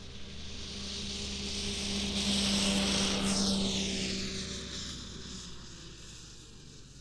Compression Ignition Subjective Noise Event Audio File - Run 1 (WAV)